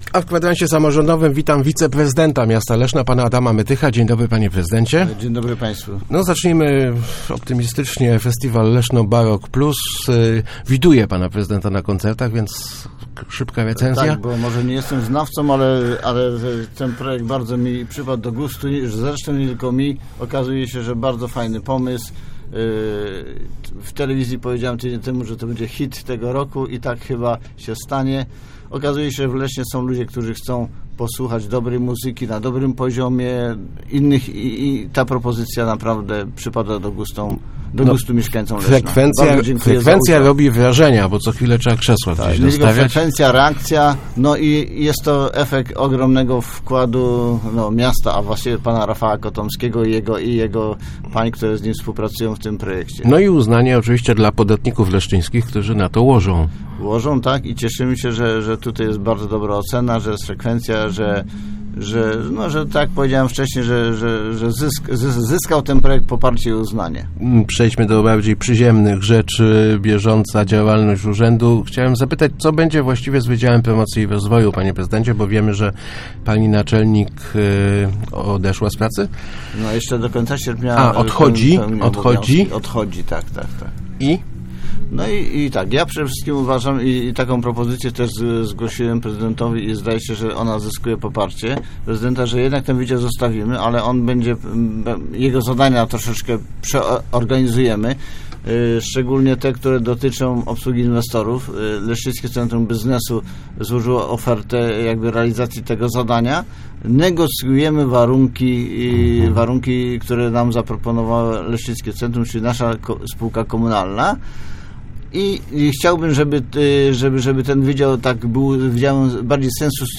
Zaproponowa�em prezydentowi, �eby nie likwidowa� Wydzia�u Promocji i Rozwoju Urz�du Miasta Leszna – powiedzia� w Kwadransie Samorz�dowym wiceprzydent Adam Mytych. Jego kompetencje zostan� jednak znacznie ograniczone – wydzia� zajmowa� si� b�dzie promocj� miasta i tak zwanymi „mi�kkimi projektami” unijnymi.